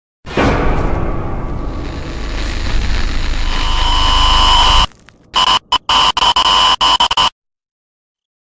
Now I want a certain part of that that I can easily adjust, except this is the file for getting caught by him, that is the static gets louder and the feedback appears.
It’s mainly ~1kHz & ~3kHz , but it’s much more complicated than that …